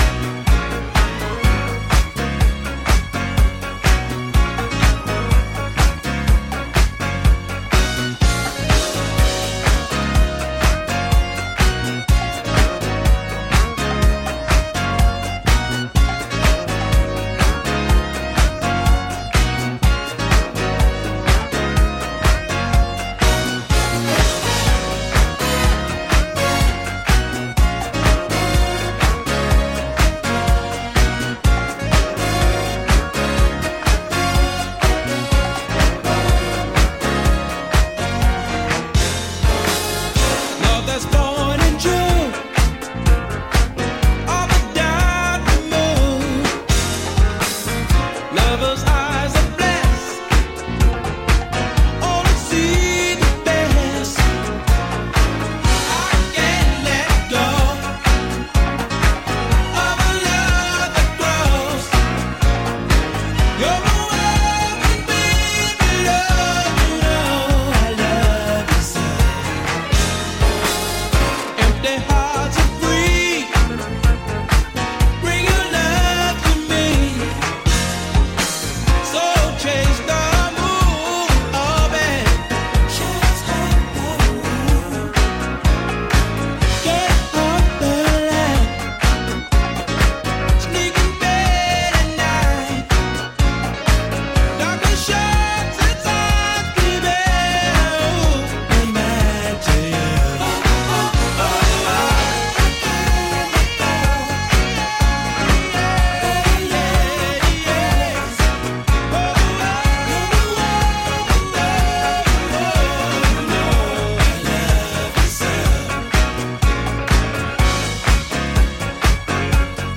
disco remix series